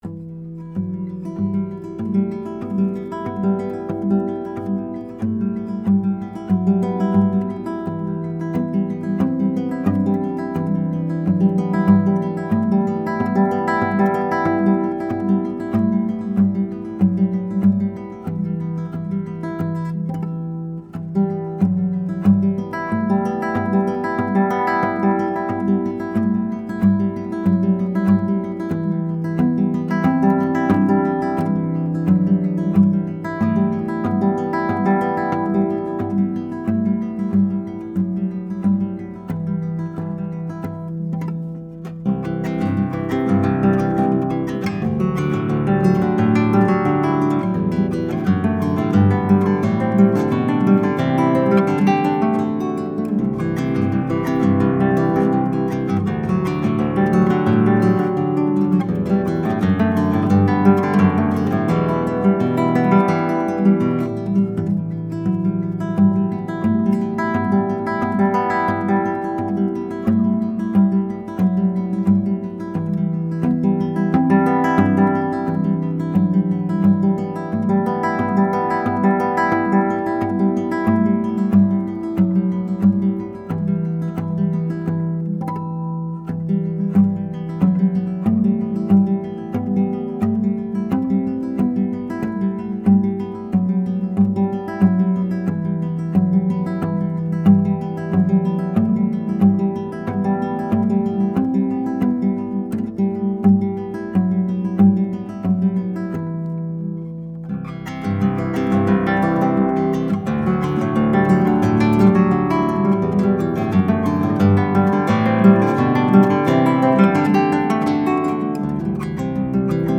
10-String Guitar
Here are 19 very quick, 1-take MP3 sound files of me playing this guitar, to give you an idea of what to expect. The guitar has amazing sympathetic resonance and sustain, as well as good power and projection, beautiful bass responce, and a very even response across the registers. These MP3 files have no compression, EQ or reverb -- just straight signal, tracked through a Sony PCM D1 flash recorder using the built-in microphones.
(original composition)